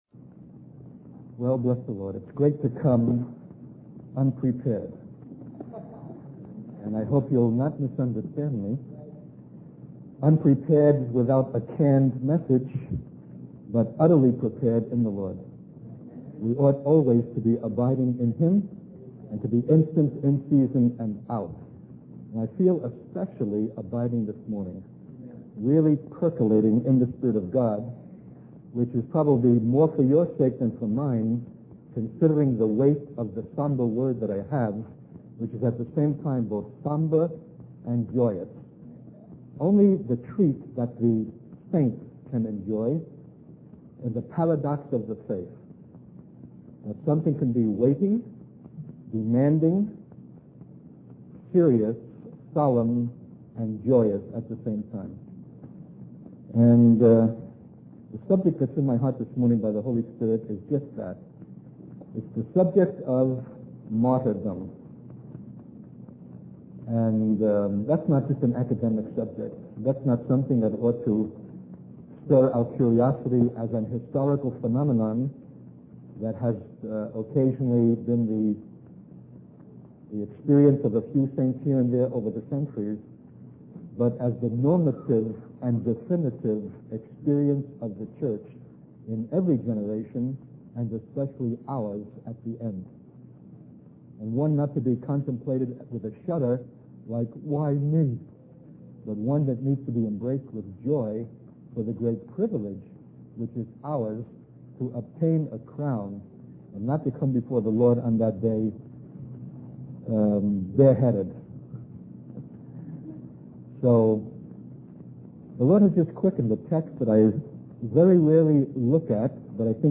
In this sermon, the speaker emphasizes the importance of living in accordance with the truth of God's word, rather than being swayed by the visible circumstances of the world.